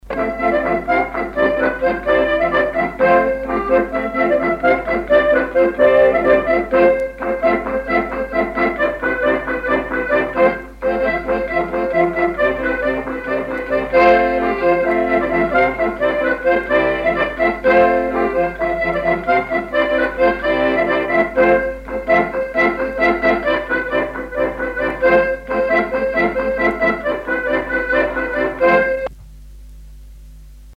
danse : gigouillette
accordéon diatonique
Pièce musicale inédite